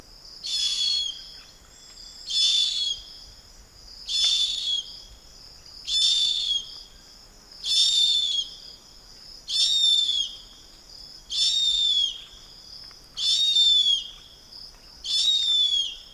A hárpia (Harpia harpyja) hangja
• Éles kiáltás: Ez a leggyakoribb hangjuk, amelyet riasztásként, territóriumuk védelmében, vagy a párjukkal való kommunikáció során hallatnak.
• Mély, rekedtes hangok: A hárpiák mélyebb, búgó hangokat is kiadnak, amelyeket főleg udvarlás során használnak.
• Hangerő: A hárpia hangja nagyon hangos, és akár több kilométerre is elhallatszik a sűrű erdőben.
Éles, пронзительный kiáltása messzire elhallatszik.
harpia-hangja.mp3